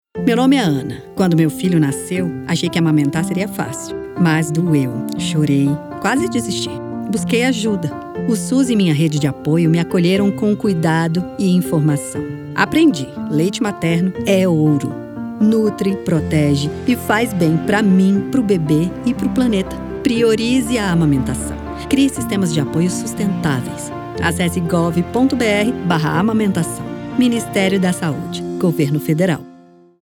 Áudio - Spot 30s - Amamentação - 1.17mb .mp3 — Ministério da Saúde